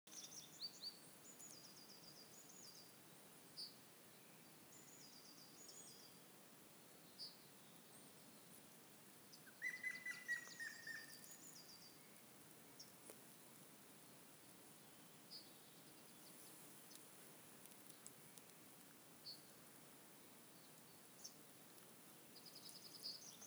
Grey-headed Woodpecker, Picus canus
Administratīvā teritorijaTukuma novads
StatusSinging male in breeding season